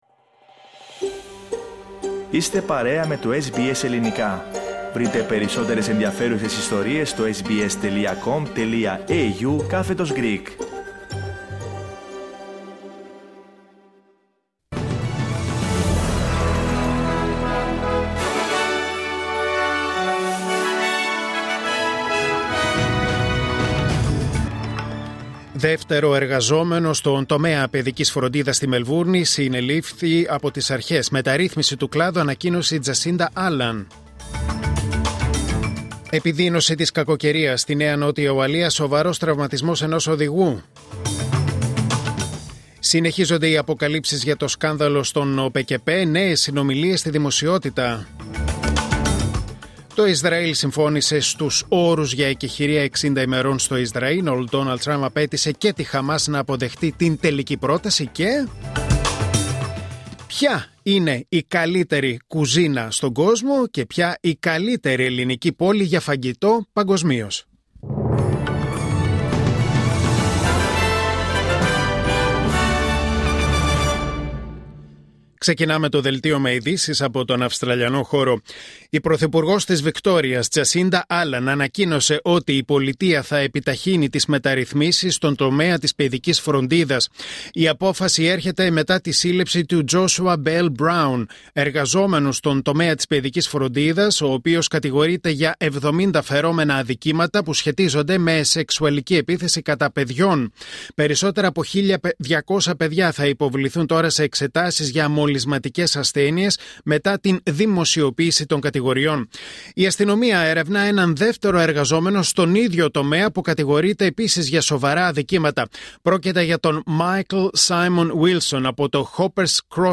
Δελτίο Ειδήσεων Τετάρτη 02 Ιουλίου 2025